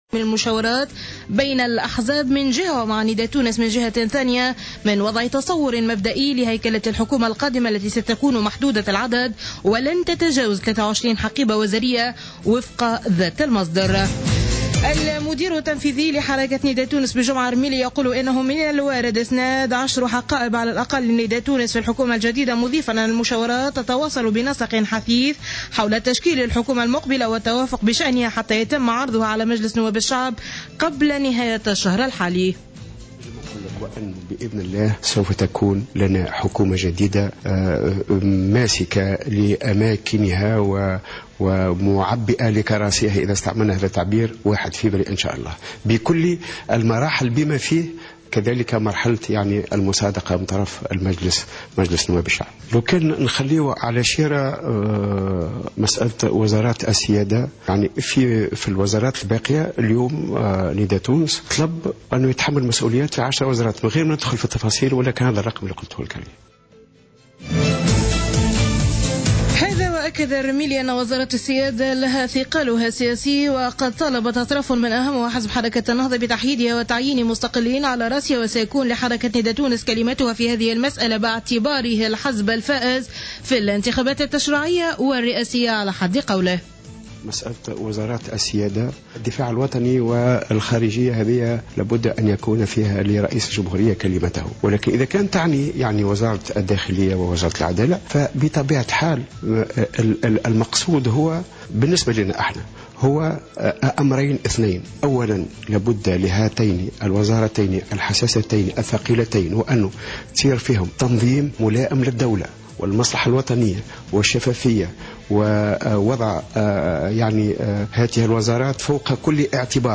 نشرة اخبار منتصف الليل ليوم الإثنين 19-01-15